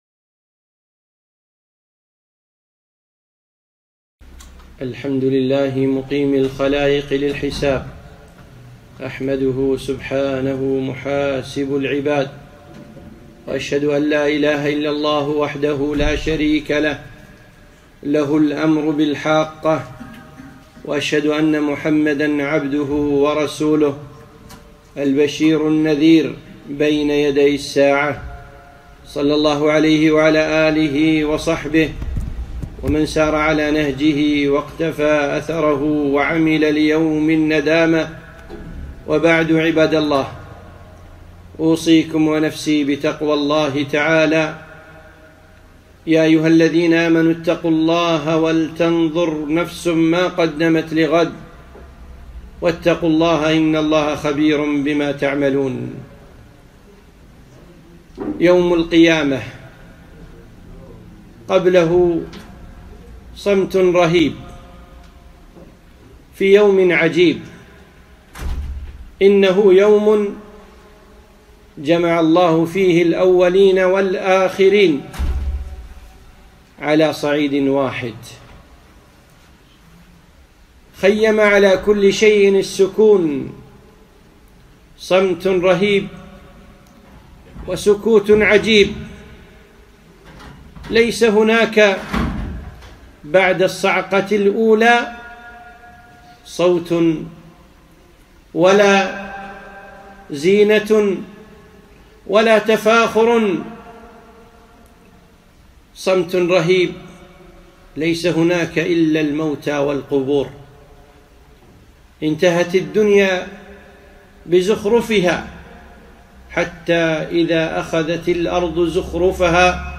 خطبة - يوم القيامة